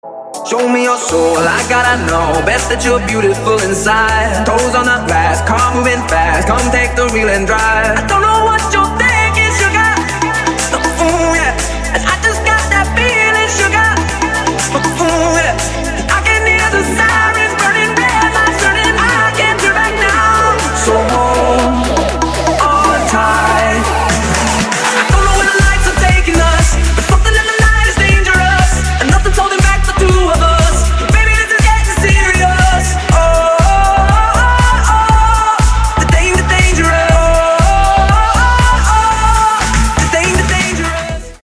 • Качество: 256, Stereo
мужской вокал
dance